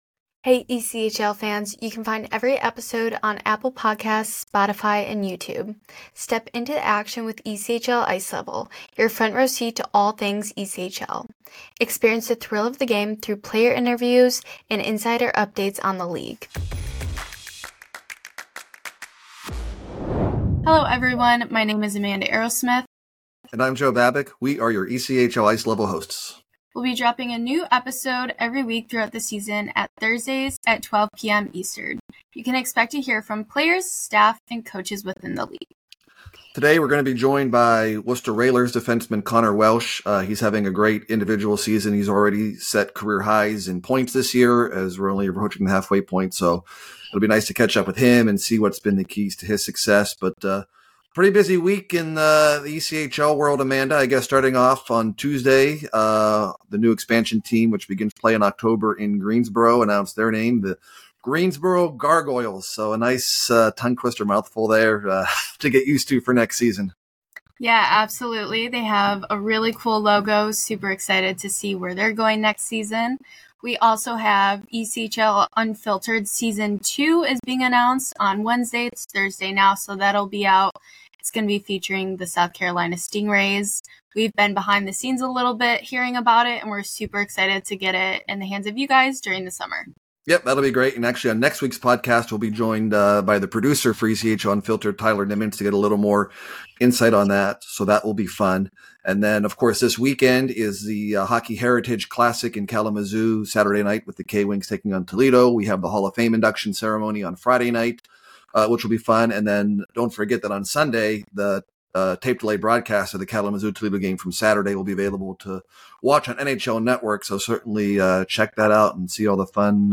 Step into the action with ‘ECHL Ice Level’, your front-row seat to all things ECHL. Experience the thrill of the game through player interviews, and insider updates on the league.